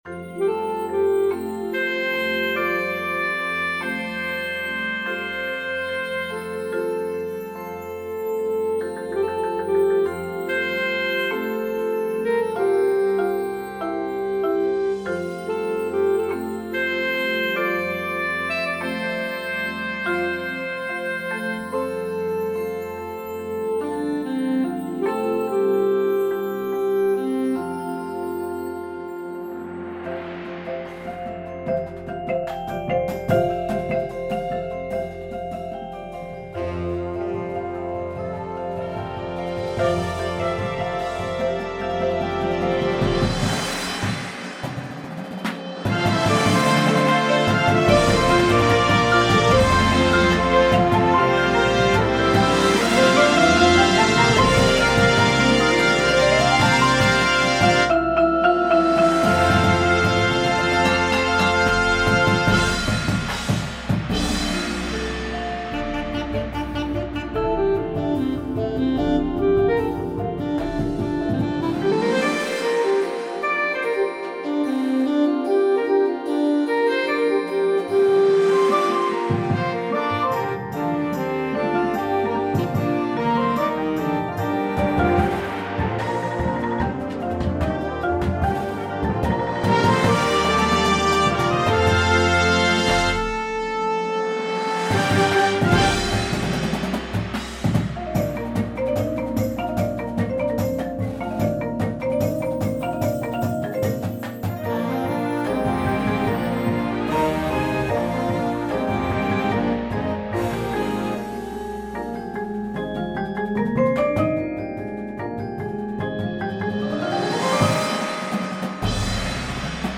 • Flute
• Clarinet 1, 2
• Alto Sax 1, 2
• Trumpet 1
• Horn in F
• Tuba
• Snare Drum
• Sound Effect Samples
• Marimba – Two parts
• Vibraphone – Two parts